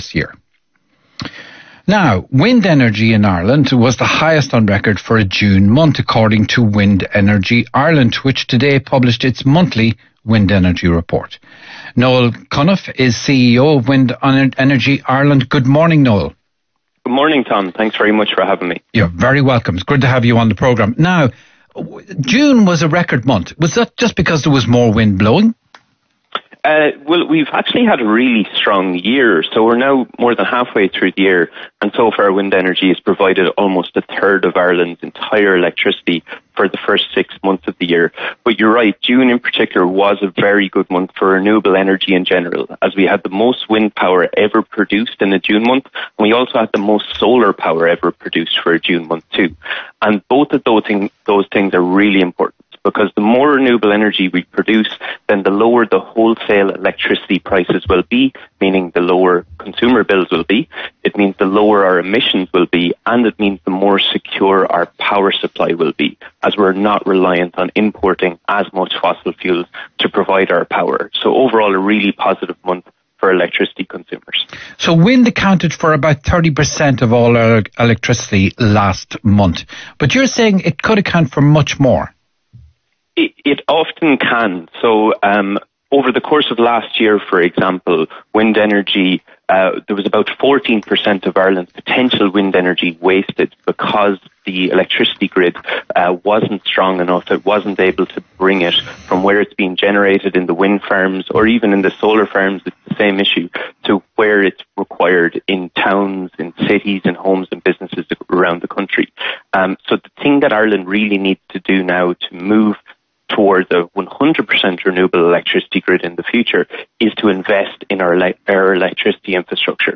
Discusses Record-Breaking June for Irish Wind Energy on Newstalk: